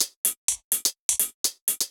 Index of /musicradar/ultimate-hihat-samples/125bpm
UHH_ElectroHatA_125-05.wav